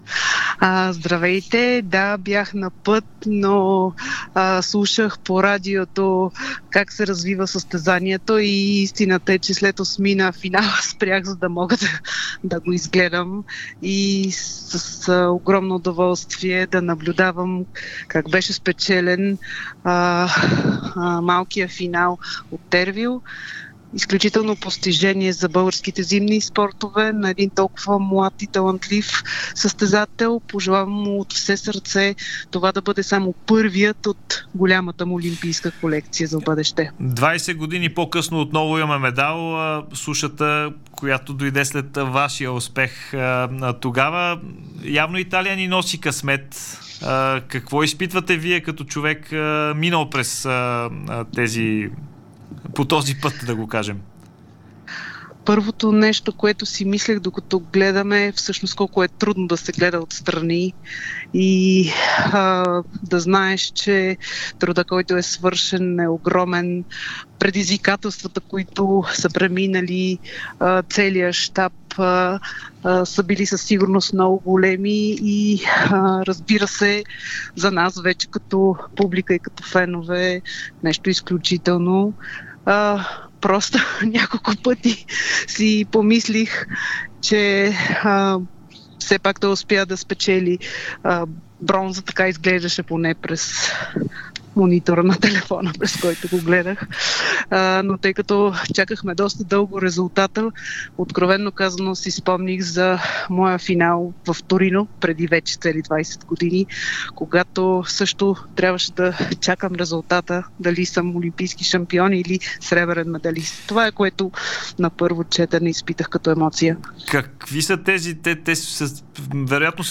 Трикратната олимпийска медалистка Евгения Раданова коментира в ефира на Дарик бронзовото отличие на Тервел Замфиров от Игрите в Милано/Кортина. Раданова вярва, че това няма да е първият медал за Замфиров предвид възрастта и потенциала му.